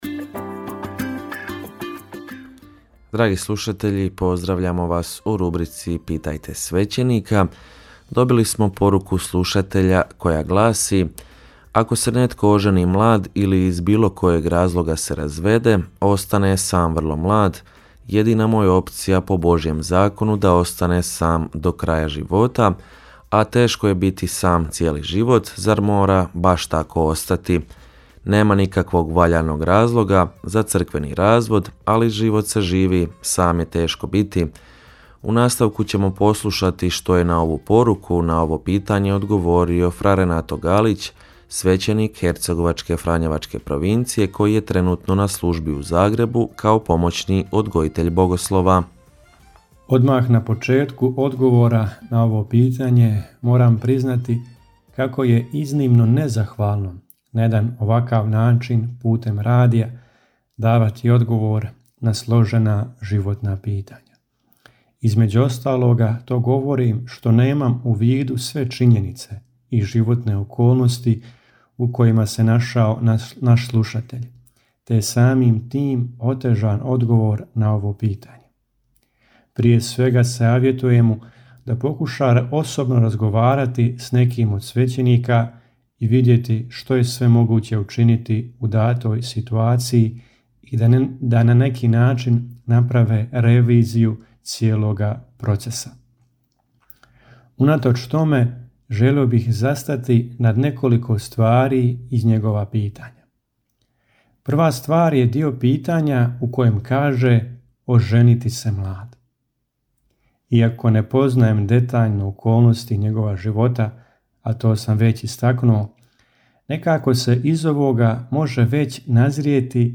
Rubrika ‘Pitajte svećenika’ u programu Radiopostaje Mir Međugorje je ponedjeljkom od 8 sati i 20 minuta, te u reprizi ponedjeljkom navečer u 20 sati. U njoj na pitanja slušatelja odgovaraju svećenici, suradnici Radiopostaje Mir Međugorje.